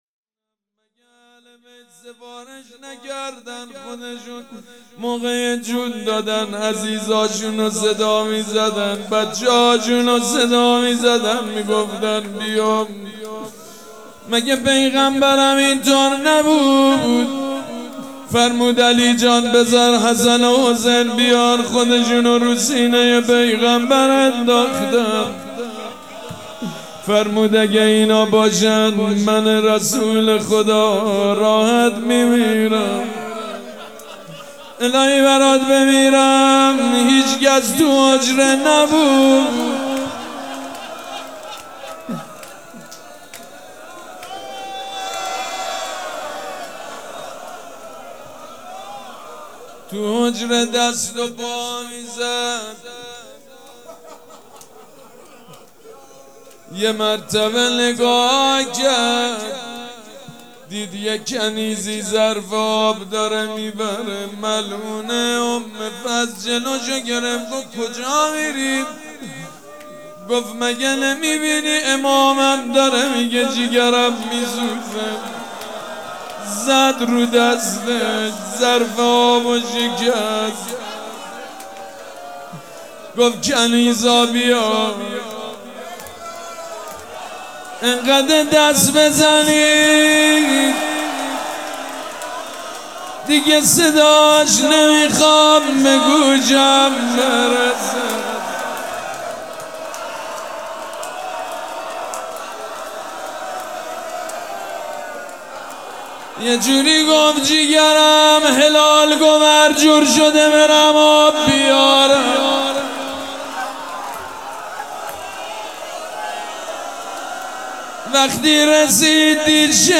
روضه
حاج سید مجید بنی فاطمه
شهادت امام جواد (ع)